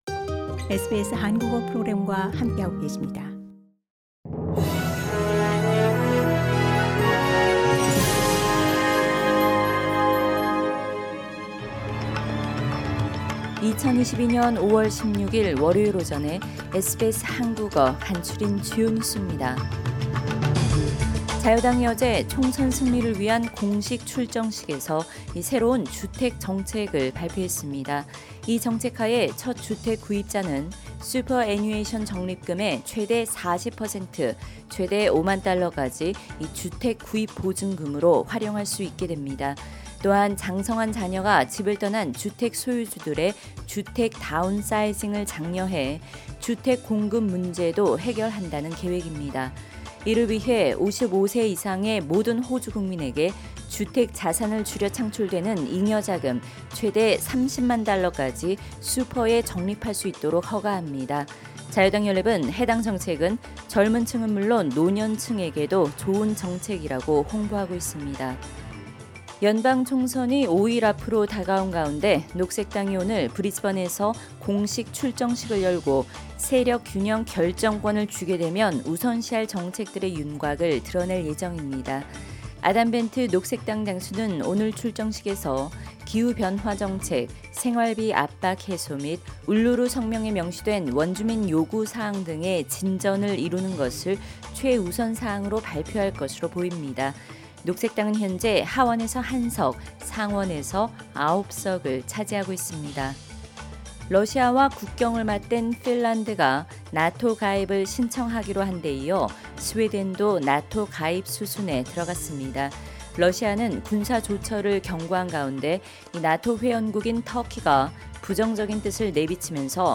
2022년 5월 16일 월요일 아침 SBS 한국어 간추린 주요 뉴스입니다.